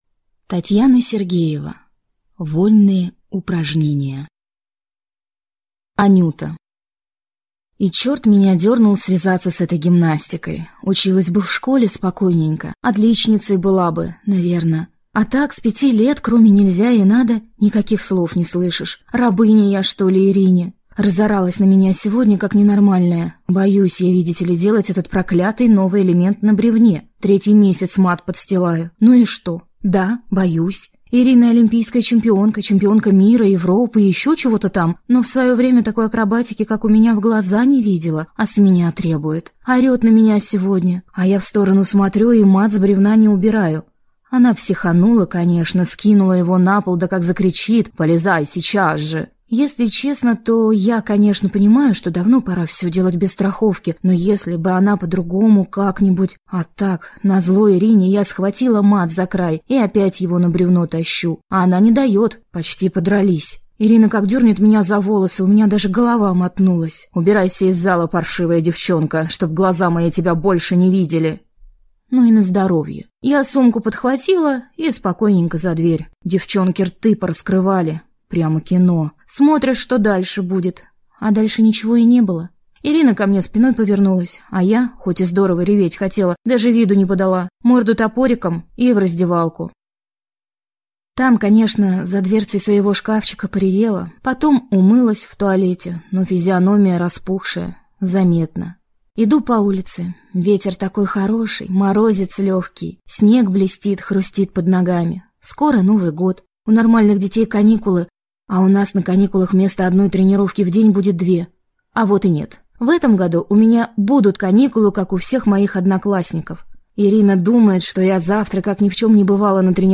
Aудиокнига Вольные упражнения